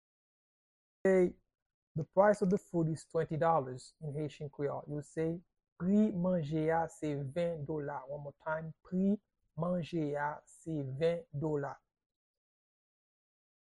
Pronunciation and Transcript:
How-to-say-The-price-of-the-food-is-20-dollars-in-Haitian-Creole-–-Pri-a-manje-a-se-ven-dola-pronunciation.mp3